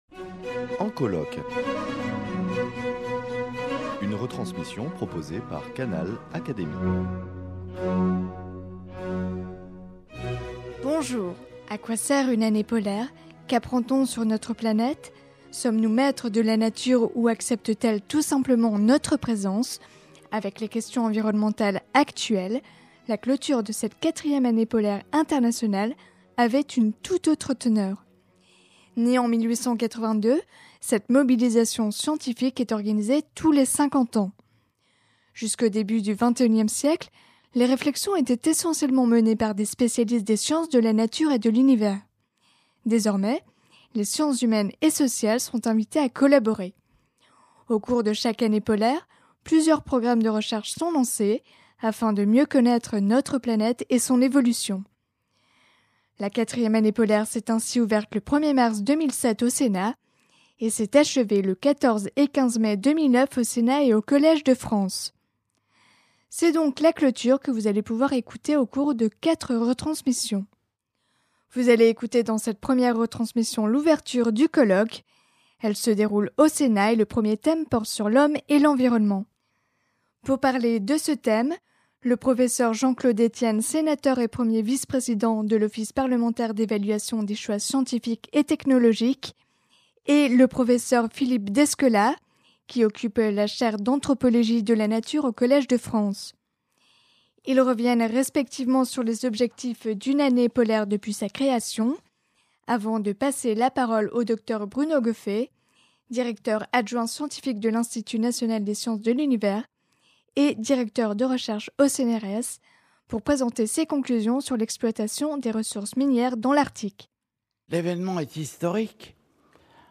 Elle s’est déroulée les 14 et 15 mai 2009 au Sénat et au Collège de France.